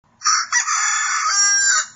Rooster Crow 2